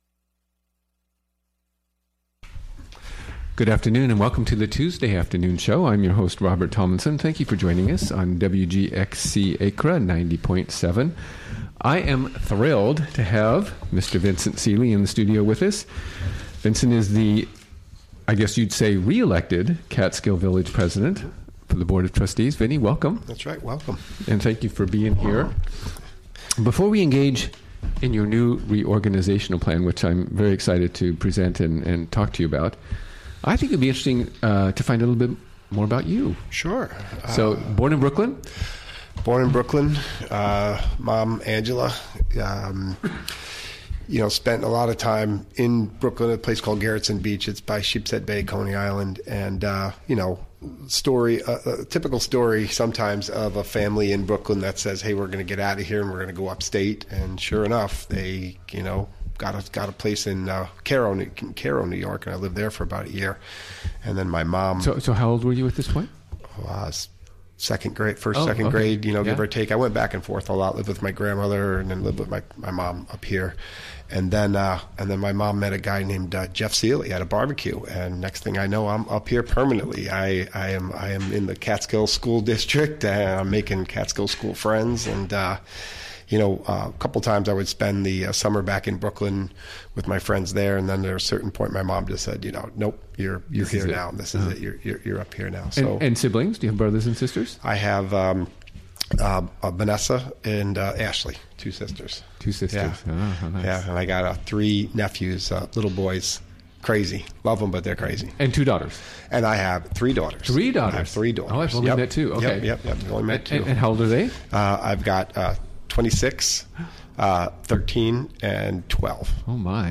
Recorded on the WGXC Afternoon Show on April 18, 2017.